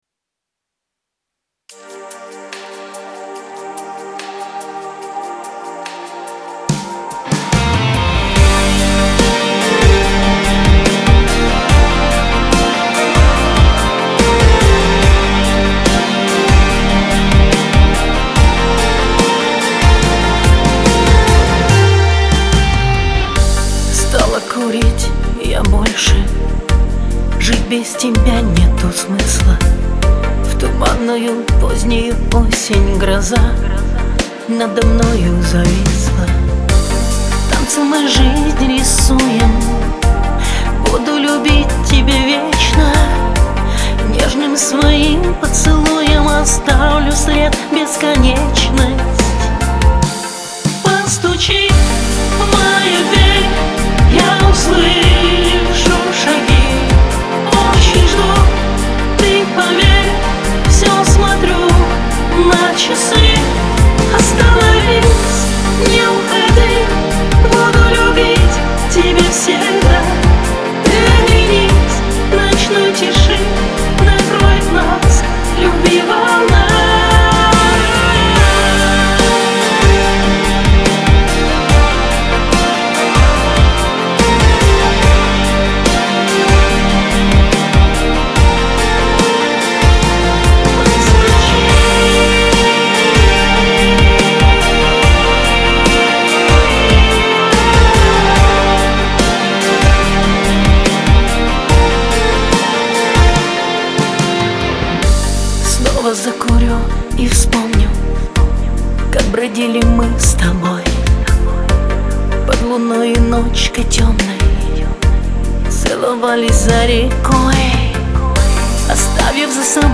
эстрада
pop
диско